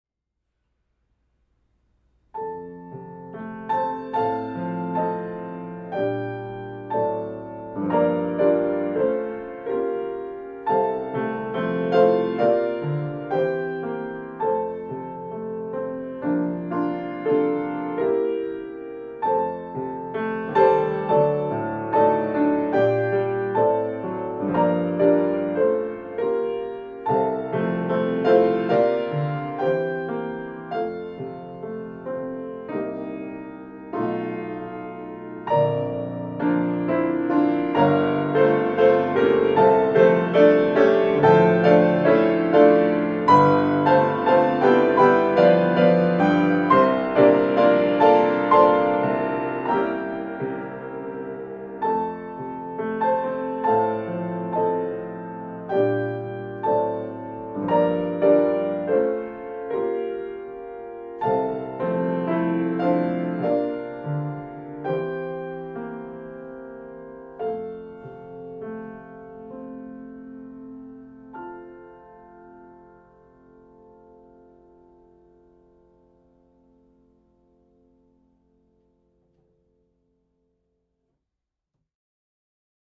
A one-stanza piano solo arrangement of the hymn